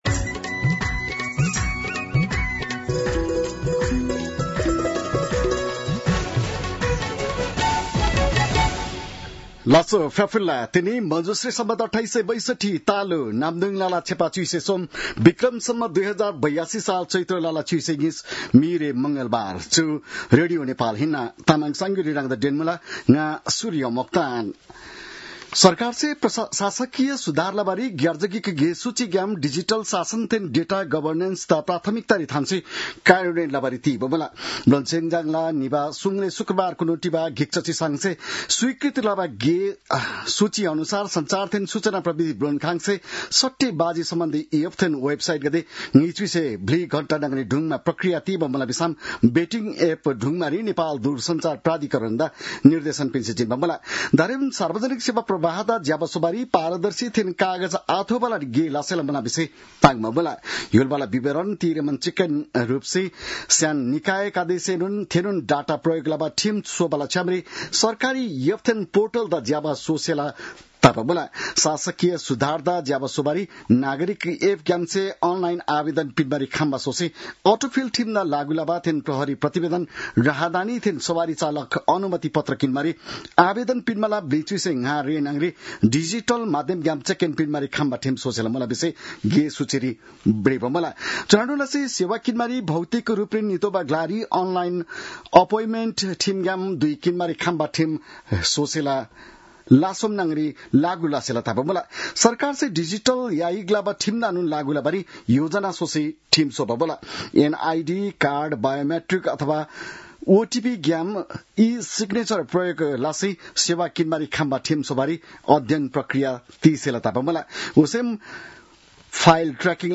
तामाङ भाषाको समाचार : १७ चैत , २०८२